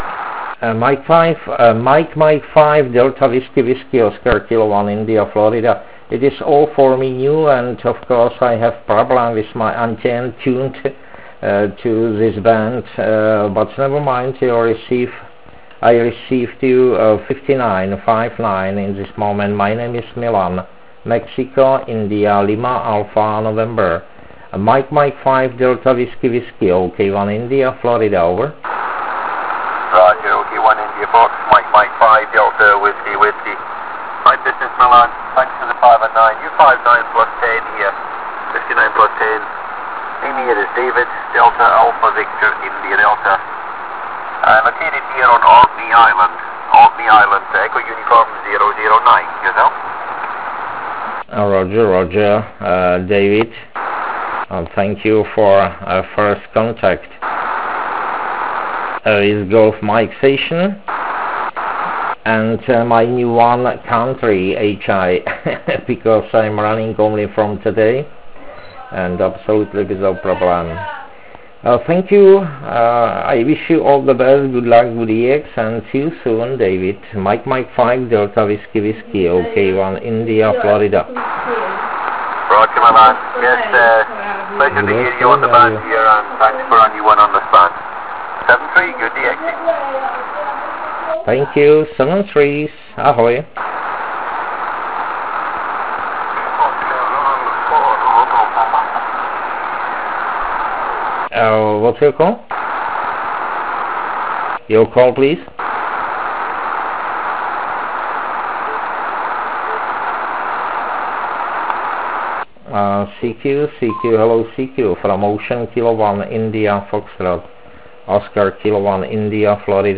Nedalo mi to, abych nevyzkoušel SSB. Jak je zde zvykem, jezdí se USB.